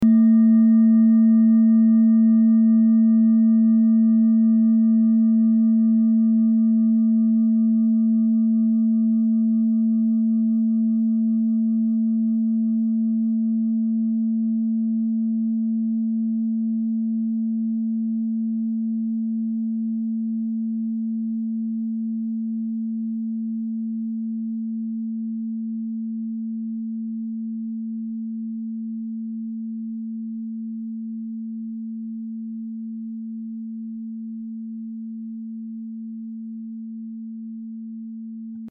Klangschalen-Typ: Bengalen und Tibet
Klangschale 3 im Set 5
Klangschale Nr.3
(Aufgenommen mit dem Filzklöppel/Gummischlegel)
klangschale-set-5-3.mp3